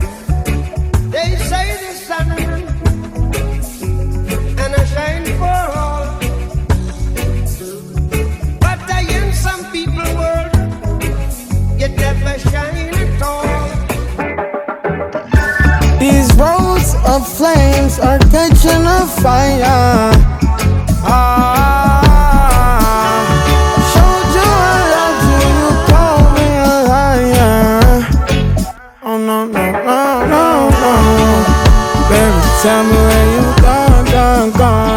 Жанр: Реггетон
# Reggae